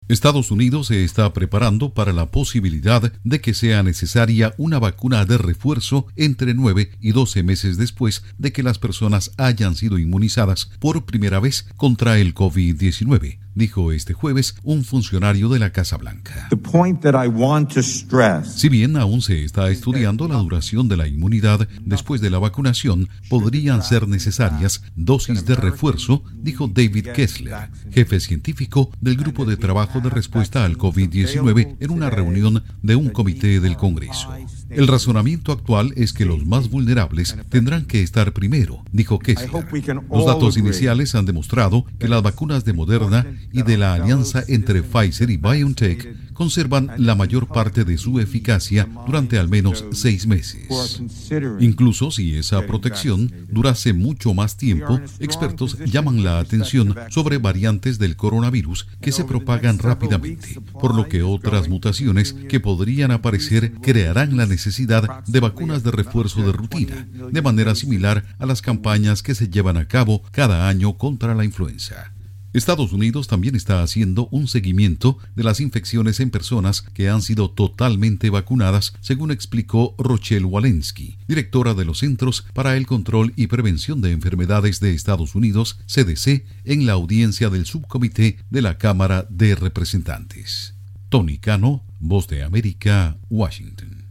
EE.UU. podría necesitar un refuerzo un año después de la vacunación del COVID-19. Informa desde la Voz de América en Washington